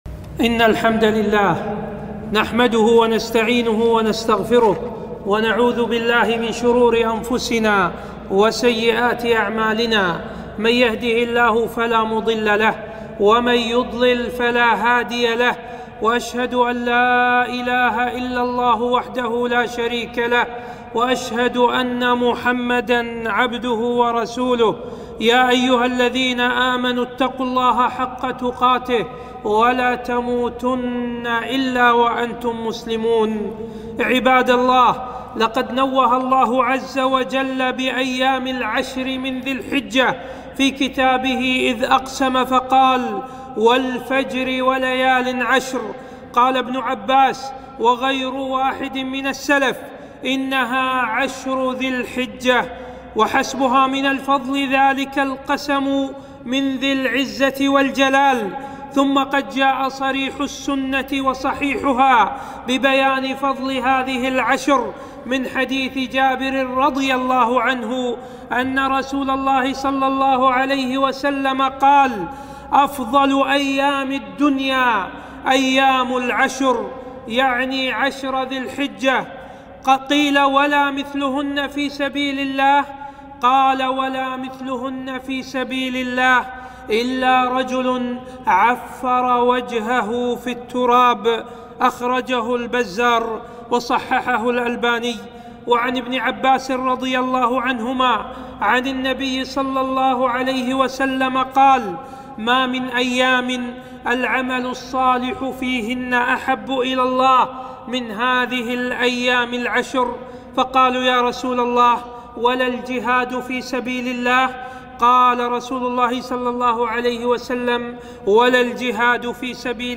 خطبة - فضل عشر ذي الحجة وأعمالها